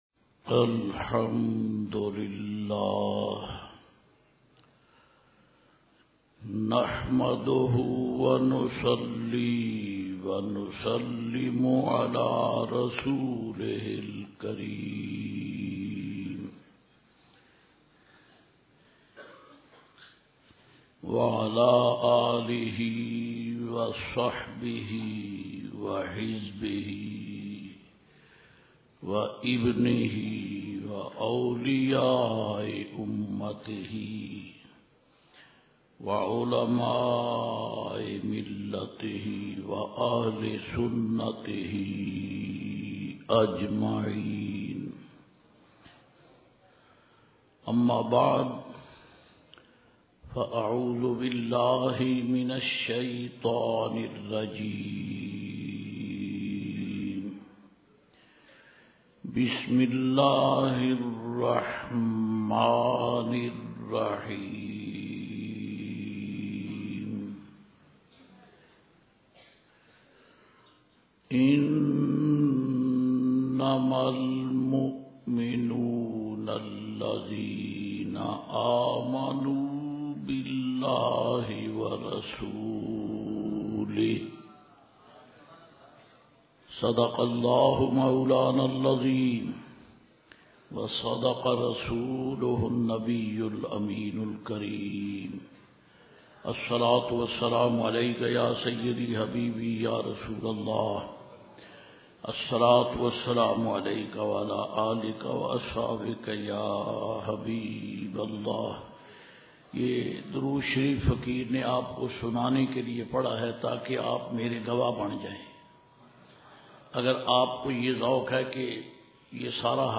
on the auspicious occasion of 96th Urs-e-Ala Hazrat Imam Ahmed Raza Khan Barelvi at Memon Masjid Muslehuddin Garden.